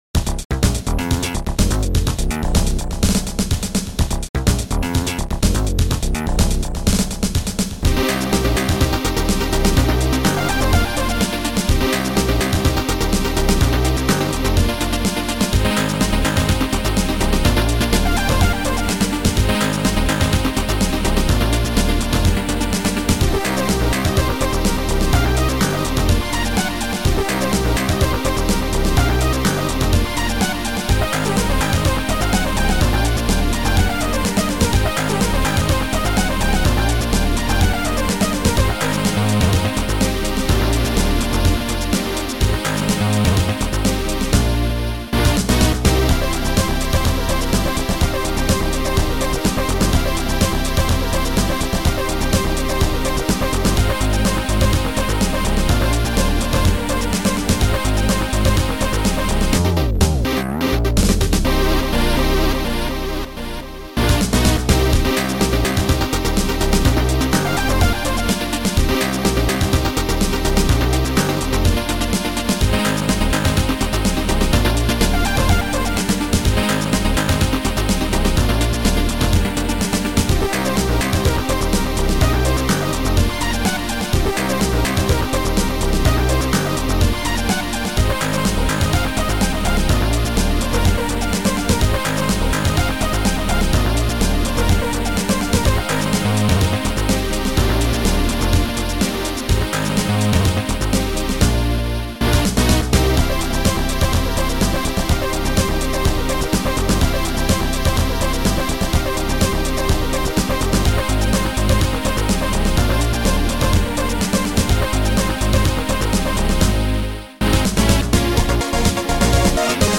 Categorized as: Music
Sound Style: Disco